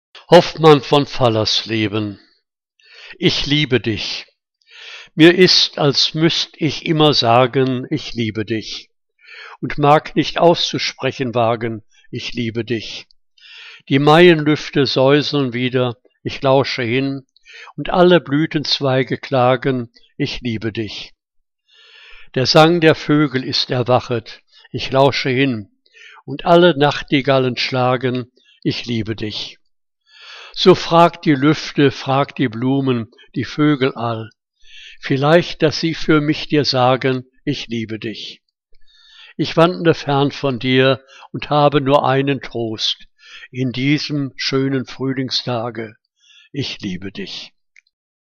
Liebeslyrik deutscher Dichter und Dichterinnen - gesprochen (A. H. Hoffmann von Fallersleben)